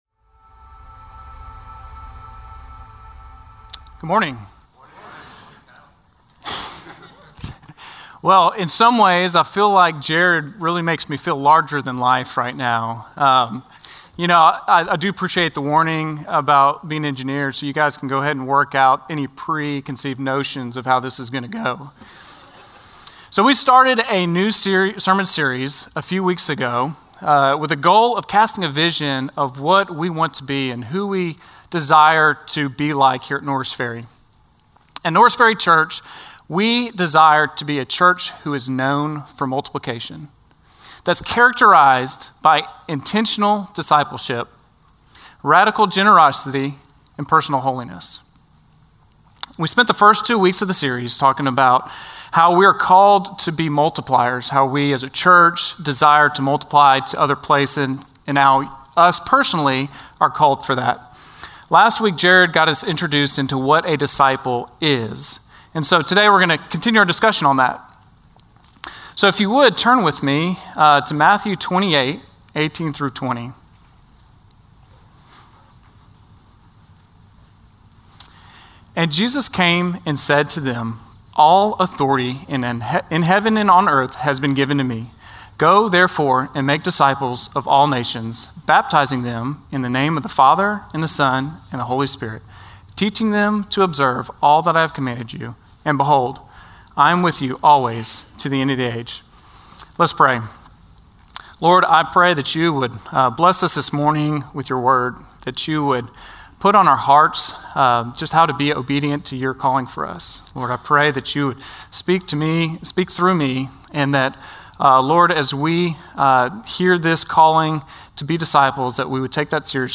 Norris Ferry Sermons June 22, 2025 -- Vision 2025 Week 4 -- Matthew 28: 18-20 Jun 22 2025 | 00:32:48 1x 00:00 / 00:32:48 Subscribe Share Spotify RSS Feed Share Link Embed